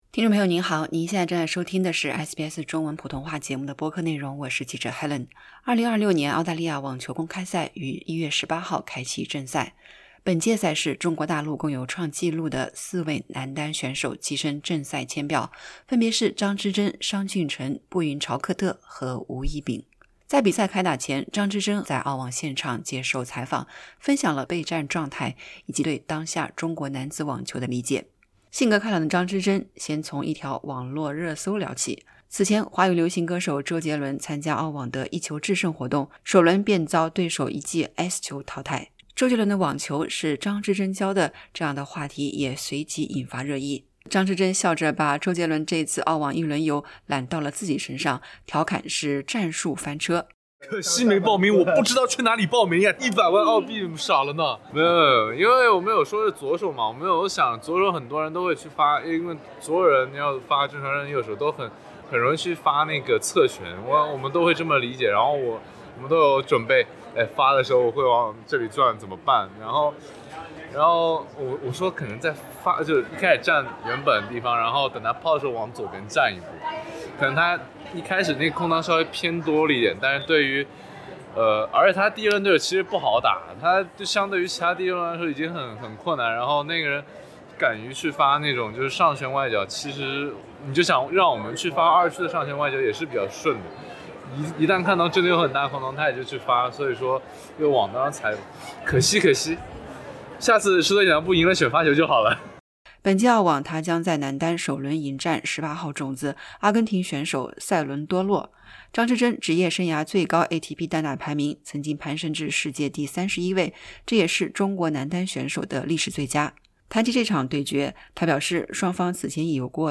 点击播放键收听完整采访 【澳网2026】“周杰伦的网球是张之臻教的” 张之臻打趣后悔没报名“一球制胜” SBS Chinese 03:54 Chinese 2026年澳大利亚网球公开赛将于1月18日开启正赛，首日比赛，中国男单选手张之臻即将迎战18号种子、阿根廷选手弗朗西斯科·塞伦多洛（Francisco Cerúndolo）。